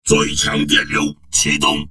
文件 文件历史 文件用途 全域文件用途 Enjo_skill_06_1.ogg （Ogg Vorbis声音文件，长度1.9秒，113 kbps，文件大小：26 KB） 源地址:地下城与勇士游戏语音 文件历史 点击某个日期/时间查看对应时刻的文件。